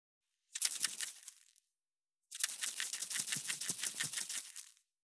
380ふりかけ,サラサラ,パラパラ,ジャラジャラ,サッサッ,ザッザッ,シャッシャッ,シュッ,パッ,
効果音厨房/台所/レストラン/kitchen